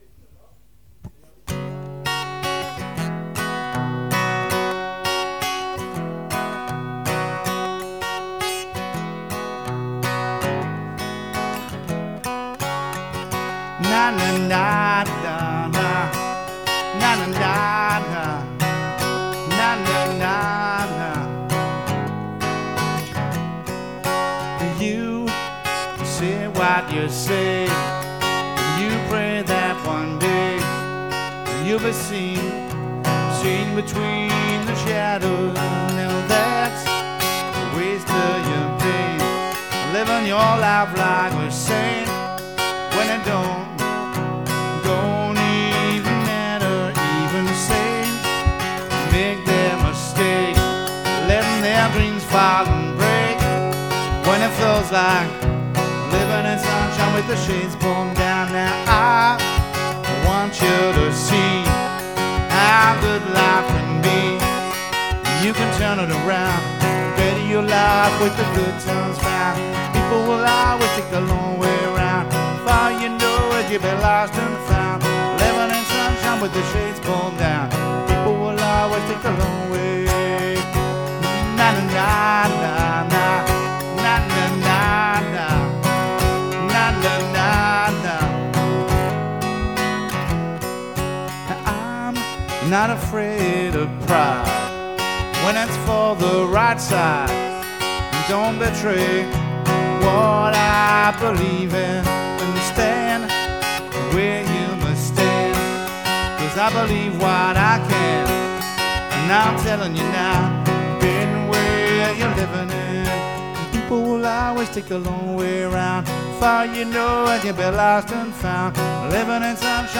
"live"